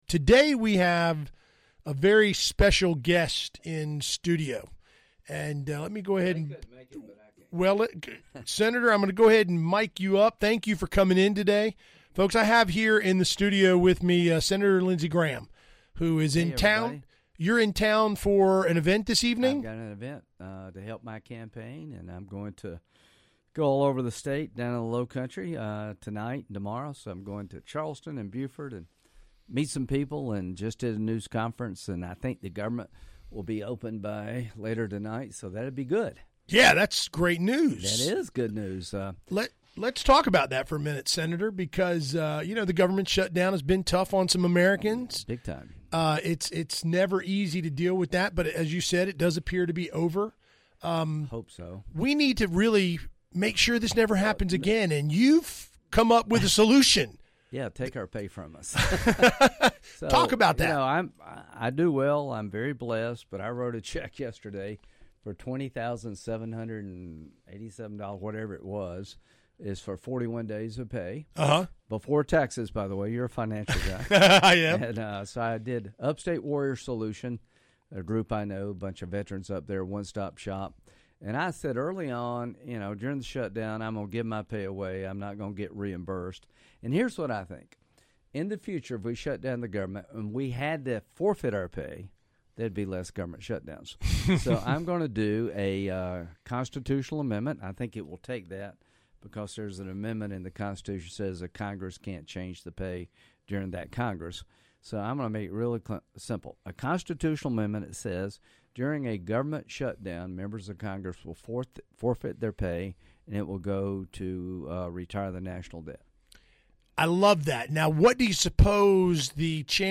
US Senator Lindsey Graham was in-studio to visit with The POINT listeners.